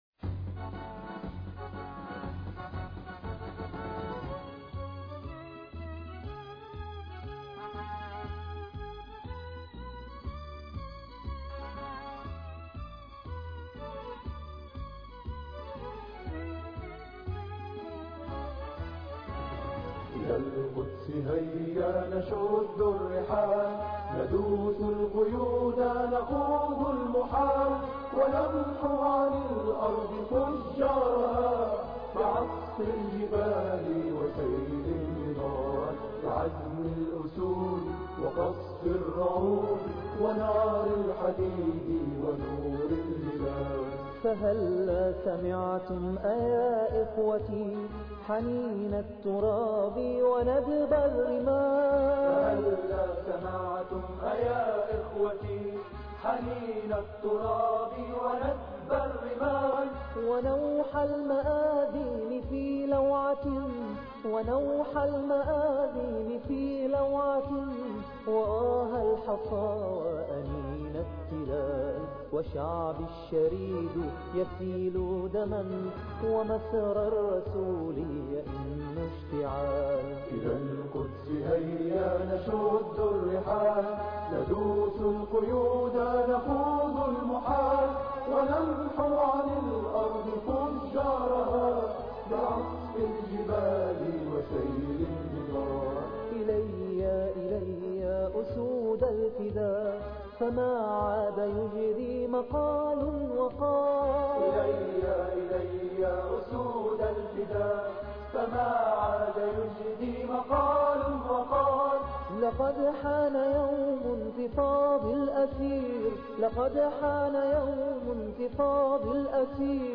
الى القدس هيا الثلاثاء 27 يناير 2009 - 00:00 بتوقيت طهران تنزيل الحماسية شاركوا هذا الخبر مع أصدقائكم ذات صلة الاقصى شد الرحلة أيها السائل عني من أنا..